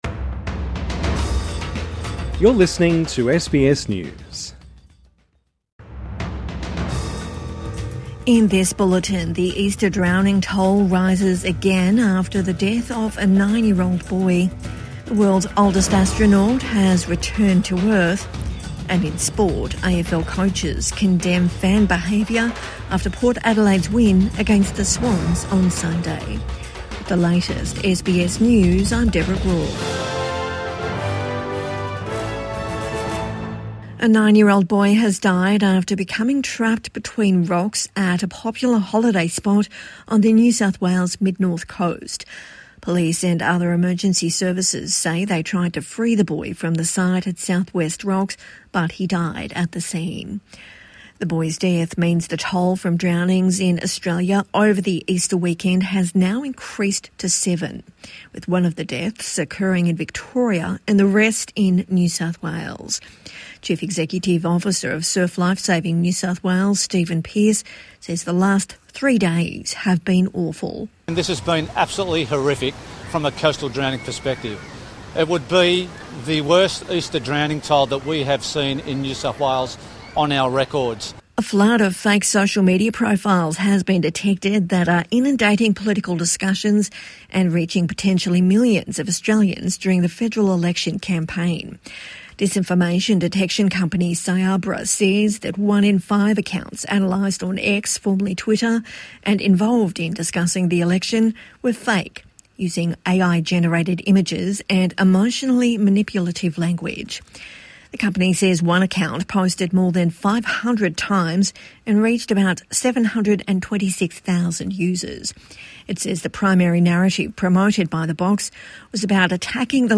Midday News Bulletin 21 April 2025